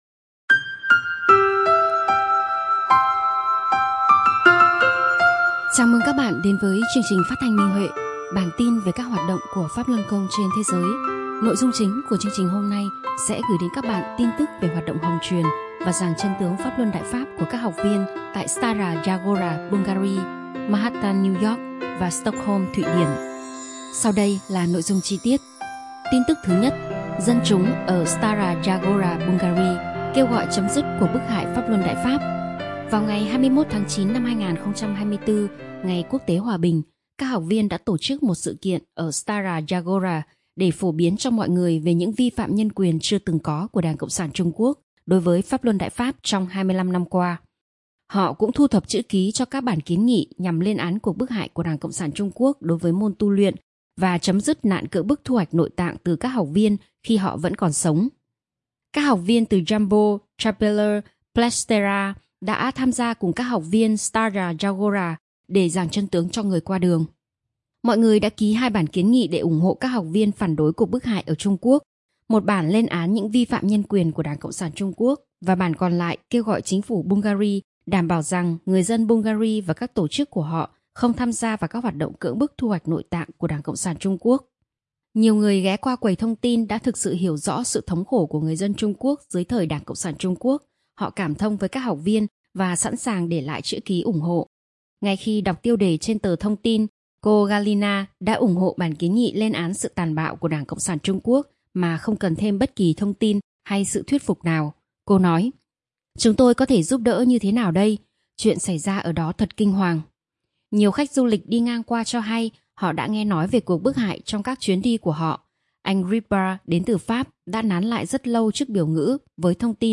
Chương trình phát thanh số 229: Tin tức Pháp Luân Đại Pháp trên thế giới – Ngày 7/10/2024